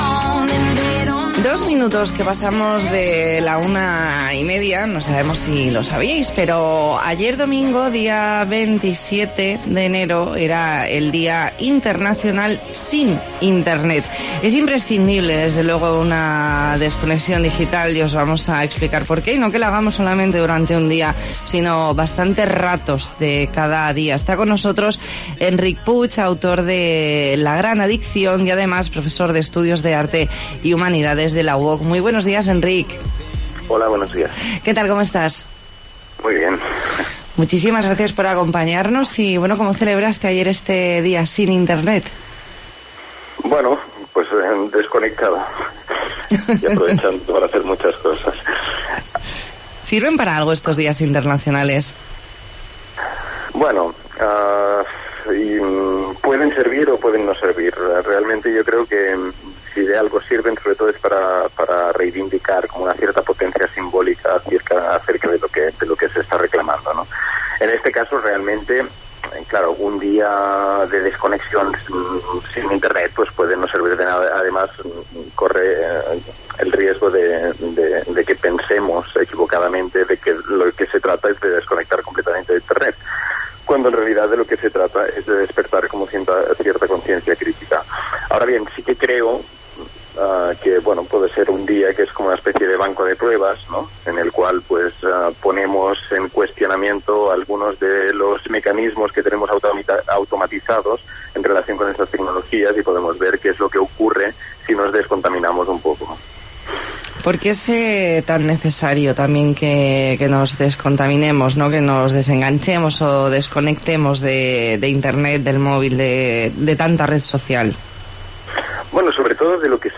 Entrevista en 'La Mañana en COPE Más Mallorca', lunes 28 de enero de 2019.